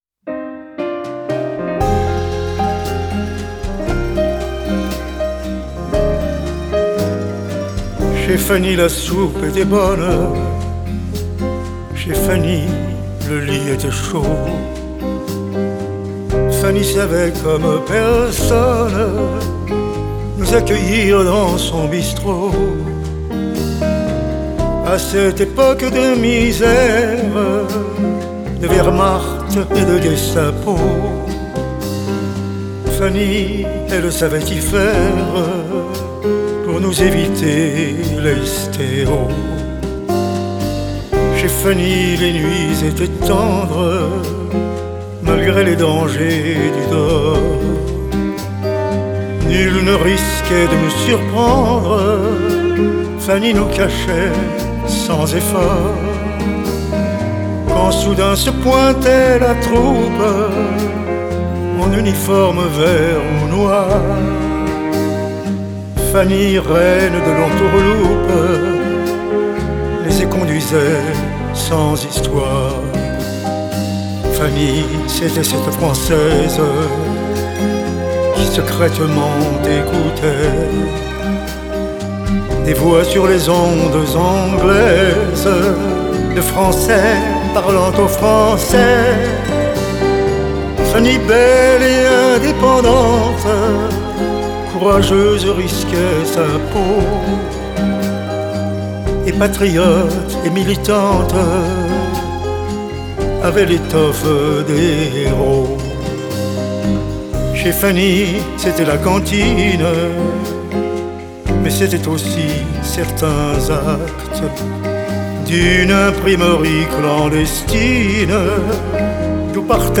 Genre: Chanson